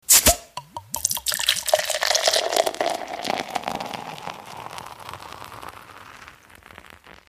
Beer - Пиво
Отличного качества, без посторонних шумов.